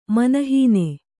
♪ mana hīne